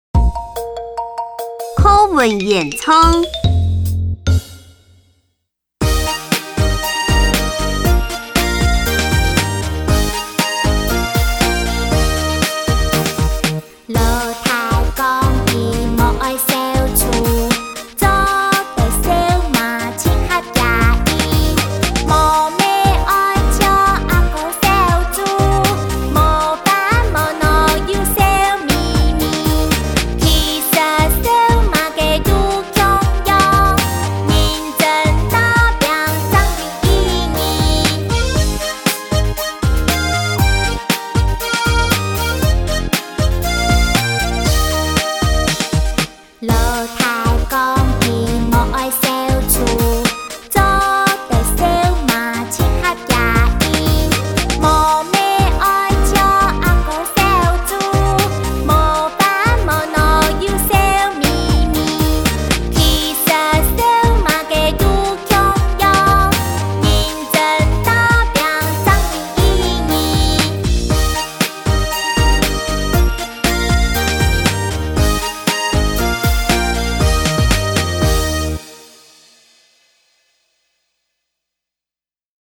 108學年度校內多語文競賽-客家語歌唱組音樂檔下載